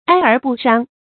哀而不傷 注音： ㄞ ㄦˊ ㄅㄨˋ ㄕㄤ 讀音讀法： 意思解釋： 哀：悲哀；傷：傷害；妨害；悲痛過分。